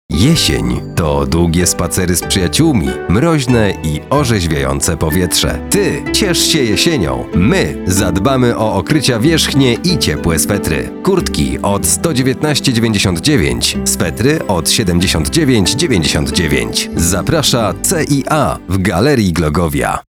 Solid, strong voiceover voice with over 10 years of studio experience.
Spot reklamowy